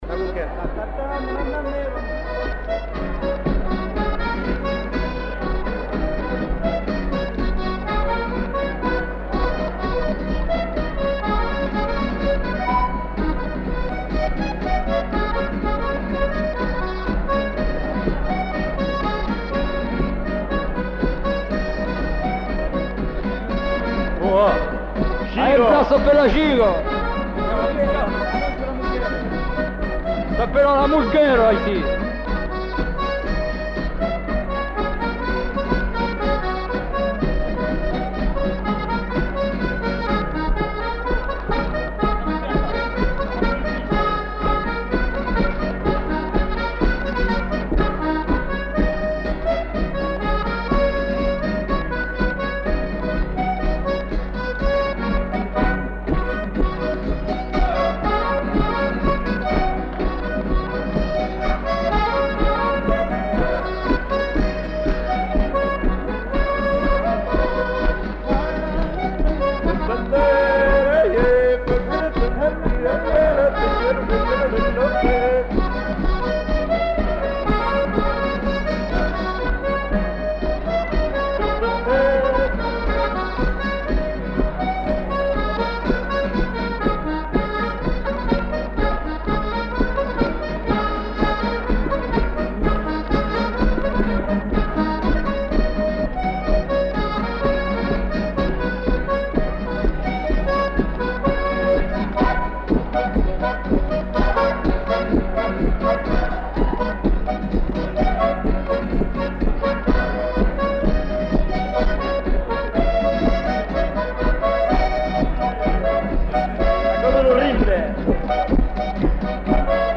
Lieu : Samatan
Genre : morceau instrumental
Instrument de musique : accordéon diatonique ; guitare
Danse : gigue
Notes consultables : Le joueur de guitare n'est pas identifié.